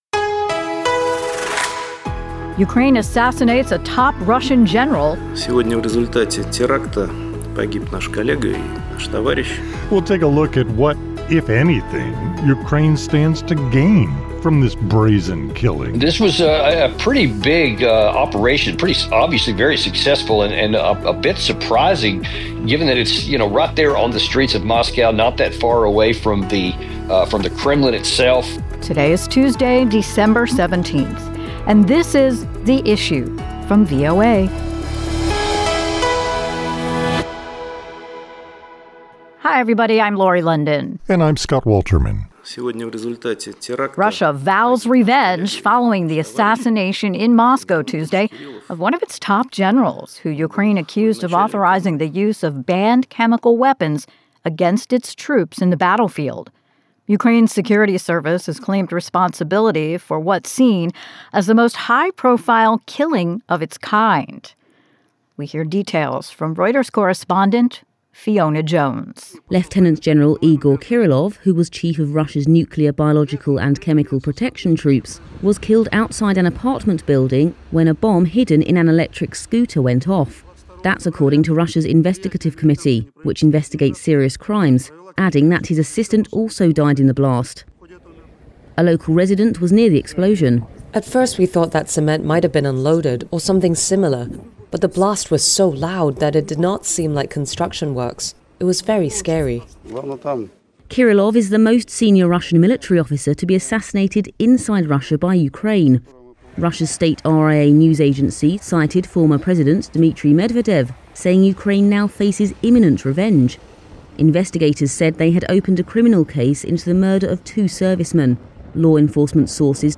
Joining us for analysis